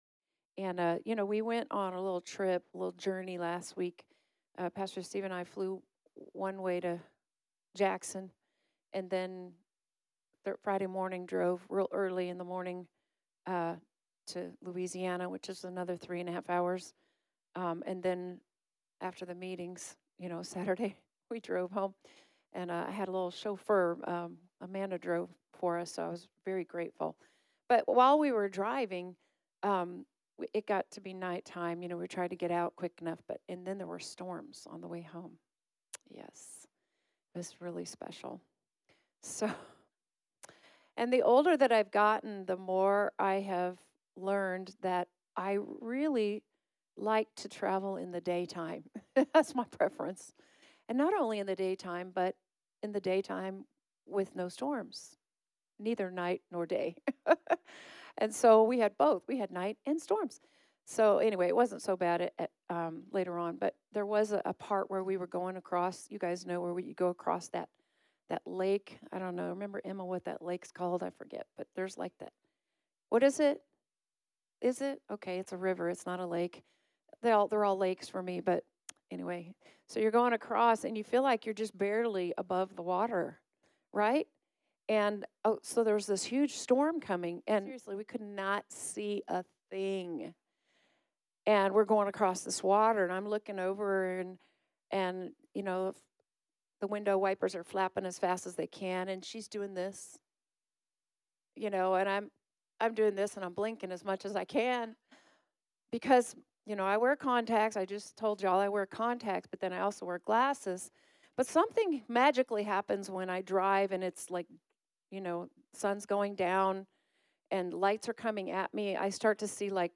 Women's Breakout Sessions